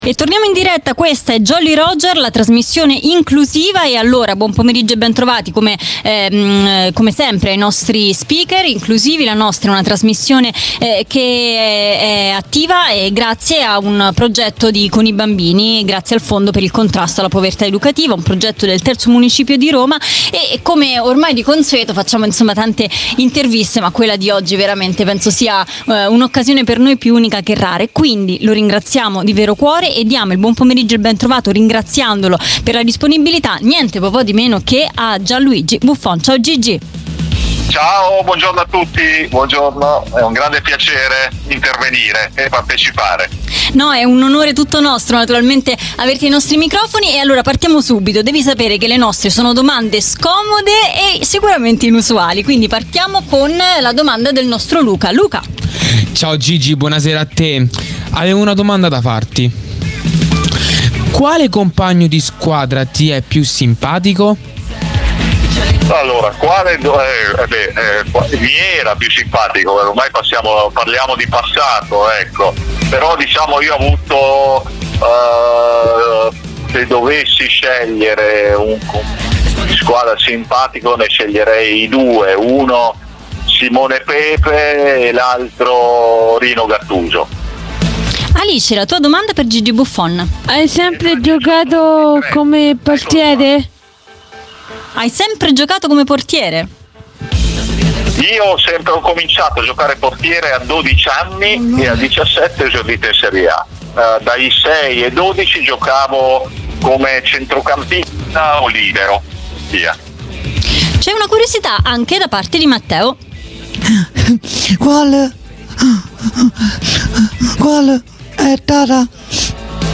Jolly Roger - Puntata 15 - Intervista a Gigi Buffon